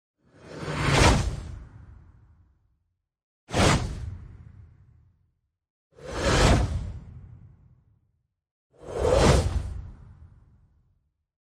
Звук ветра для перехода кадра
Серия звуков из четырех порывов ветра для монтажа